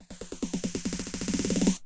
T2_snrroll
T2_snrroll.wav